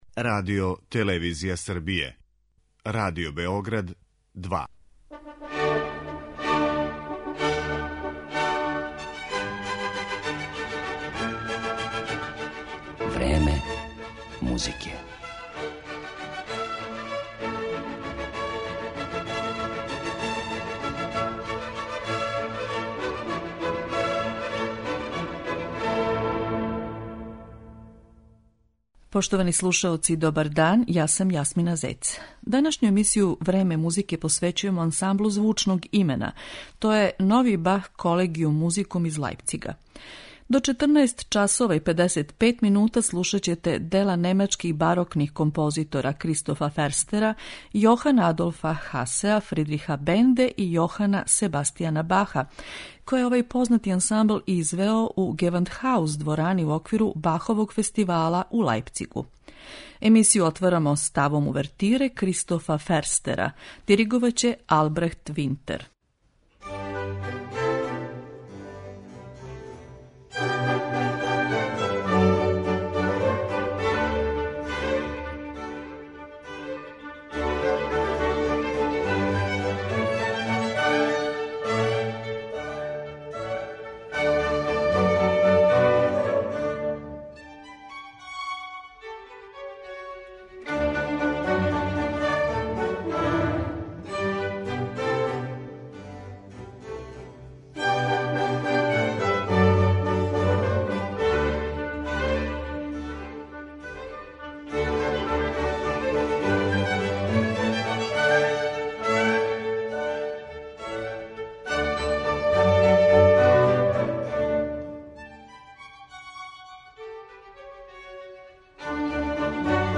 Емитоваћемо дела немачких барокних композитора у извођењу 'Новог Бах колегијум музикум ансамбла'.